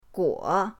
guo3.mp3